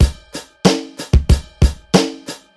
标签： 醇厚 8位 混响 环境 背景 回声 窒息 数字 低保真 大气
声道立体声